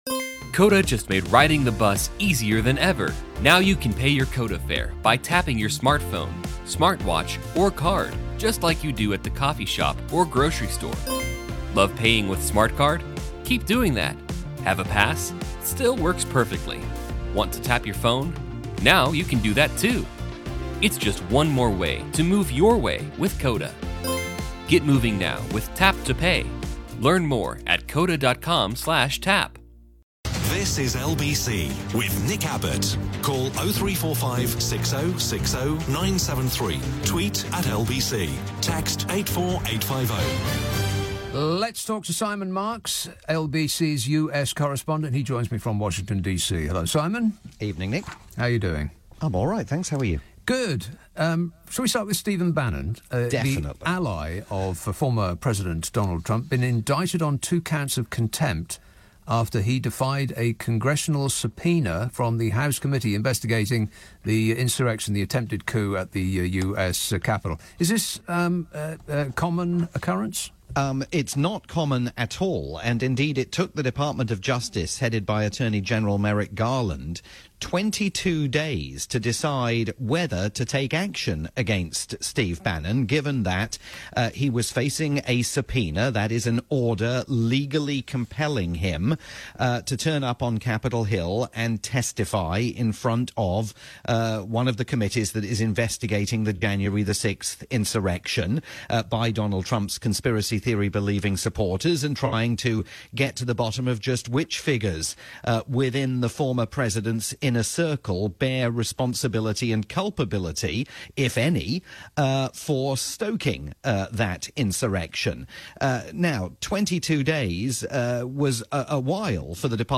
weekly Sunday night / Monday morning chat